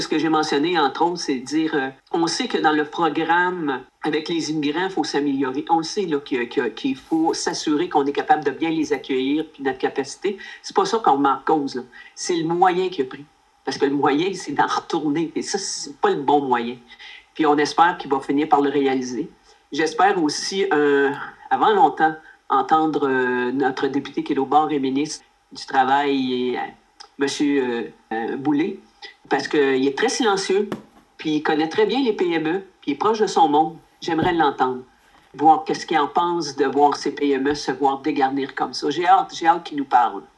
Lors de la séance du conseil de Nicolet de lundi soir, la mairesse suppléante, France Trudel, a déploré le mutisme du ministre Jean Boulet dans l’épineux dossier des travailleurs étrangers.